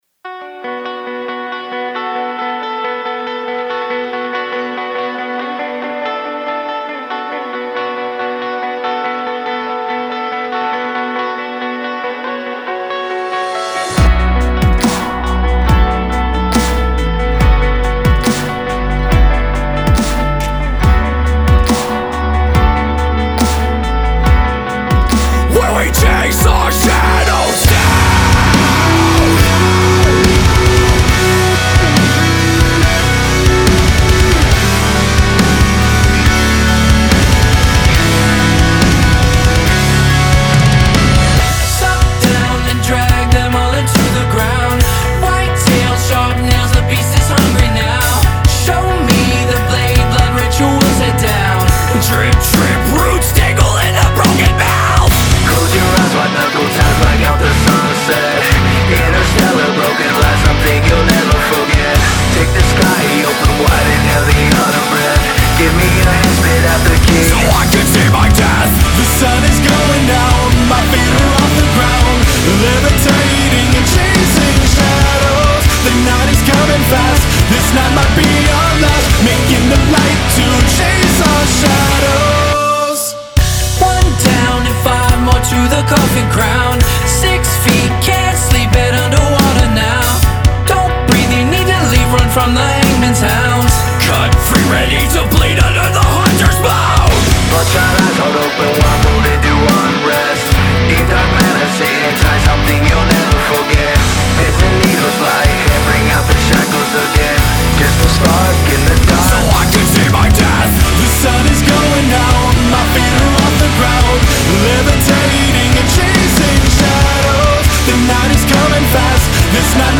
powerhouse five-piece metal band